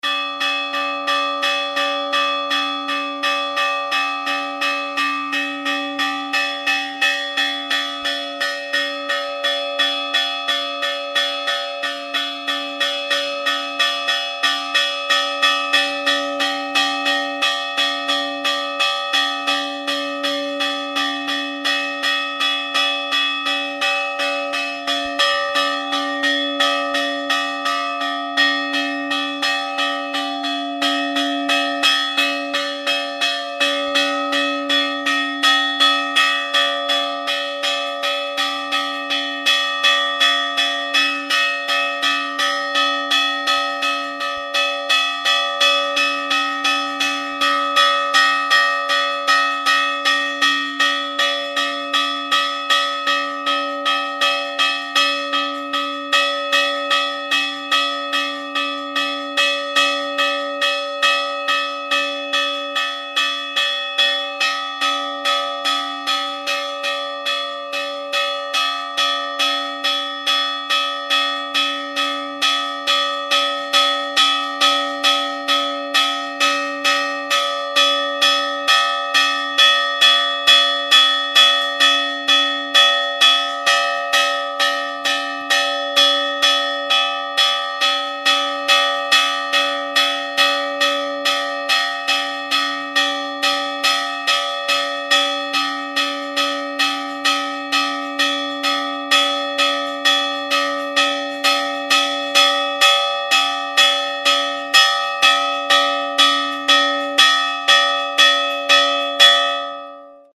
Toques de campanas en formato MP3
Alarma 1:50 alarma badajazos